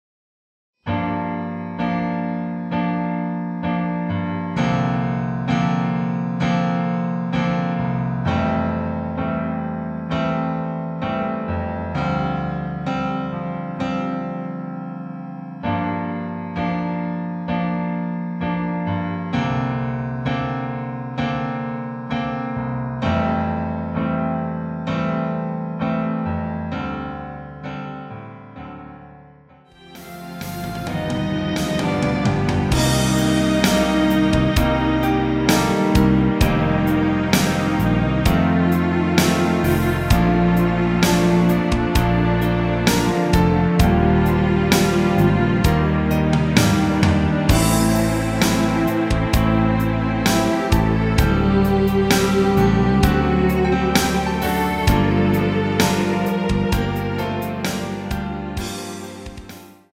MR 입니다.
원곡의 보컬 목소리를 MR에 약하게 넣어서 제작한 MR이며